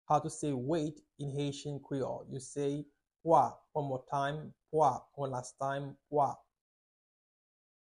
Listen to and watch “Pwa” audio pronunciation in Haitian Creole by a native Haitian  in the video below:
19.How-to-say-Weight-in-Haitian-Creole-–-Pwa-pronunciation.mp3